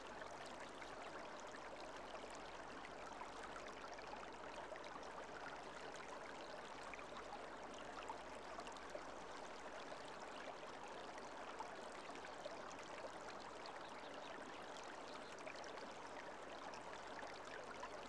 SND_water_stream_slow_loop.ogg